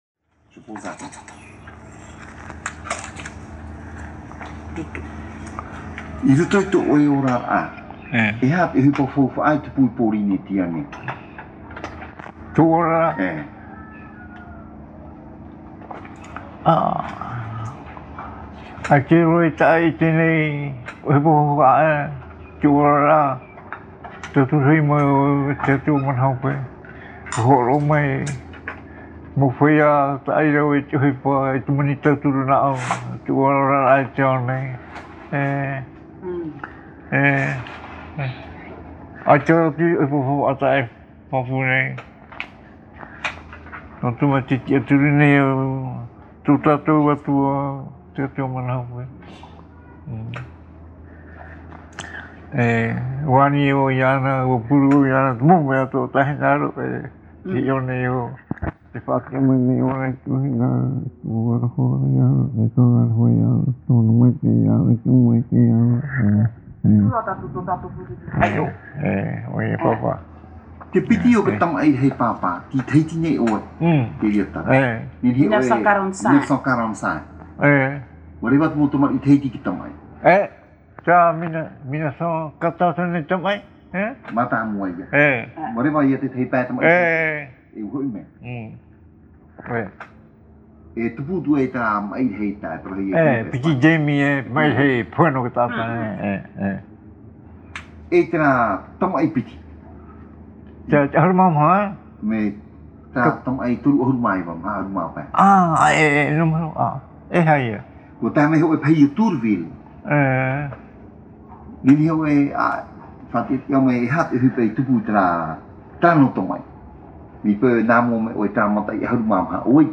Descriptif de l'interview
Interview réalisée à Tīpaerui sur l’île de Tahiti.
Papa mātāmua / Support original : cassette audio